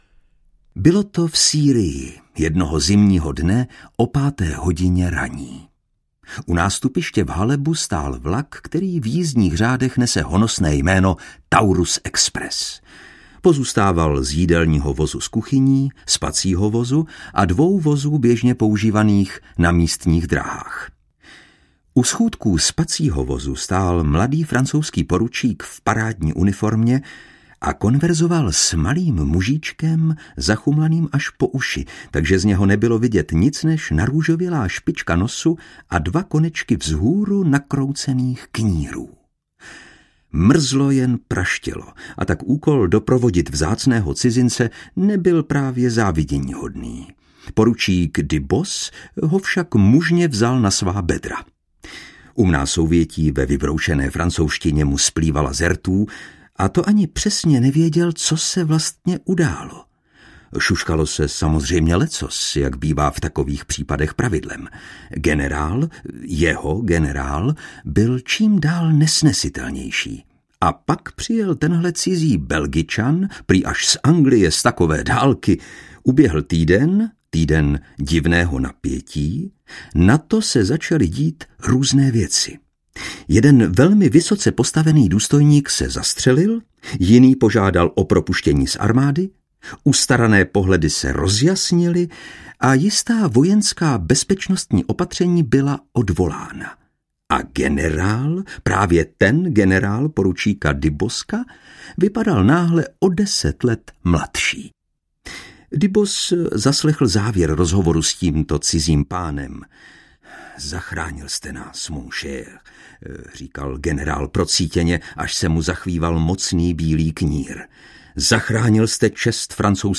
Vypočujte si ukážku audioknihy